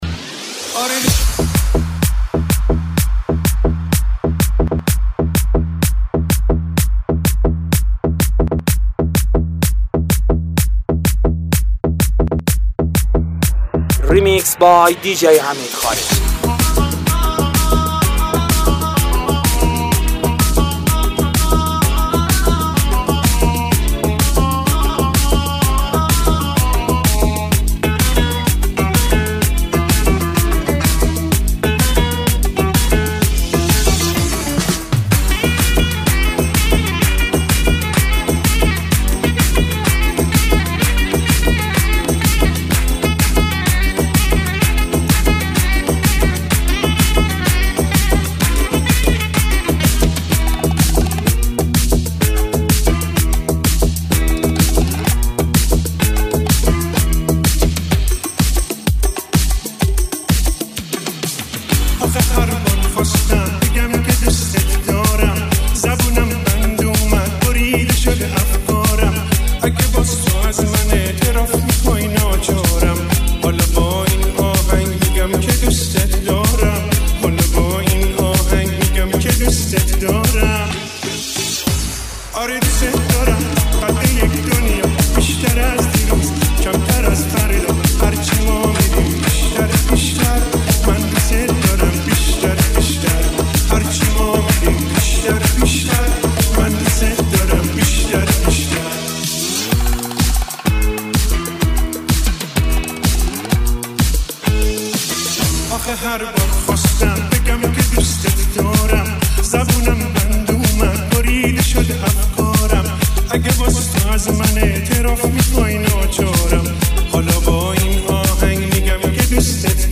ریمیکس پرطرفدار و خاطره‌انگیز
ریمیکس قدیمی